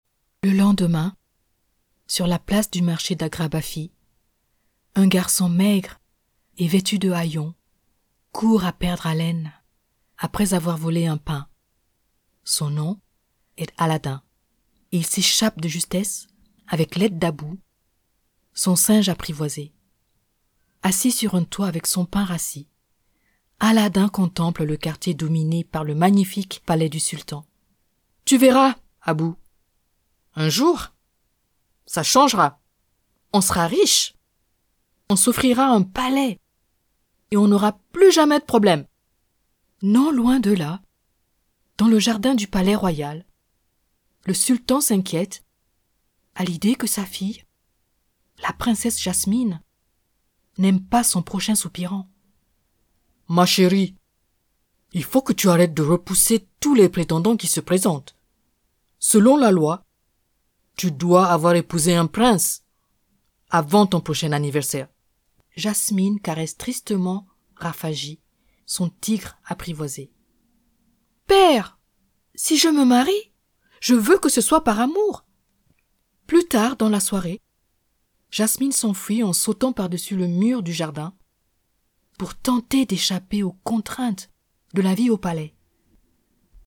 Audio Book Samples
Children's (French)
Authentic, Character